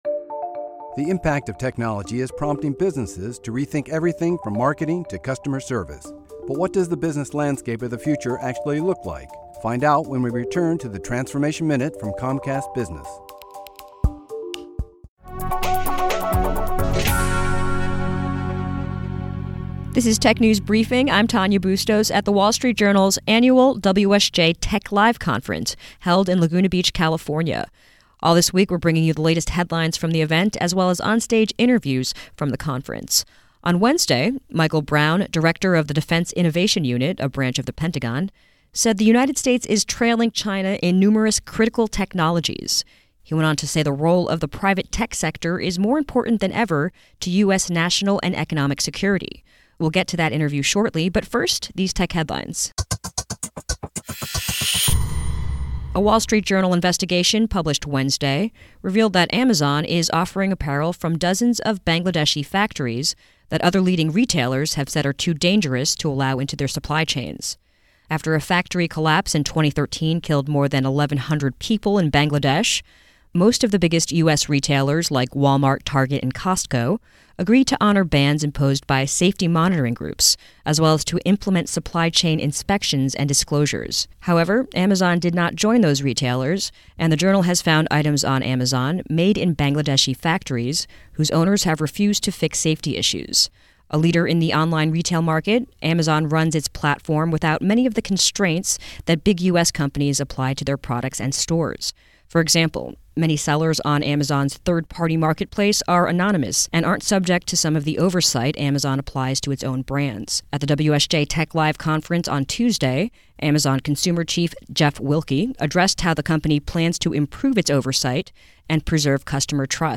At WSJ's Tech Live conference, Michael Brown, director of the Defense Innovation Unit, said that while the U.S. has a lead in certain technologies, the list of tech where China has the edge is extensive, including: 5G cellular networks, drones, batteries, hypersonic systems, wind and solar energy, as well as cryptocurrency.